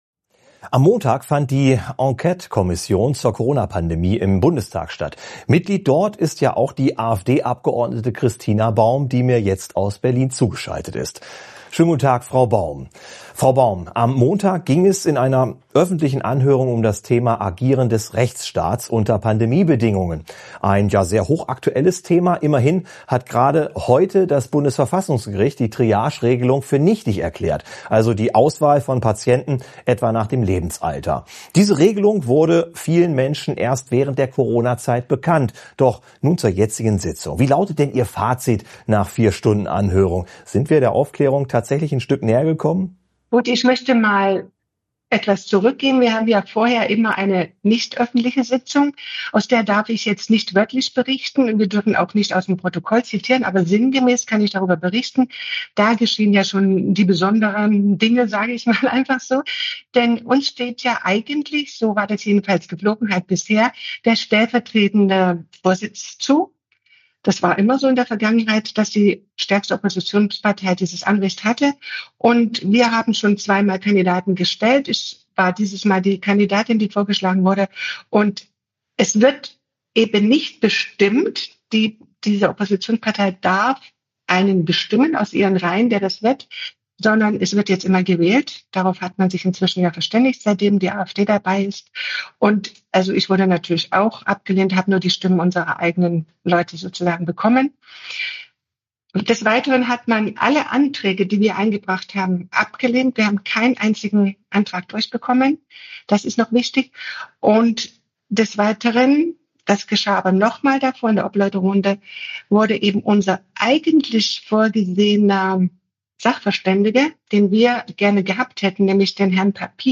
Christina Baum im Gespräch mit AUF1.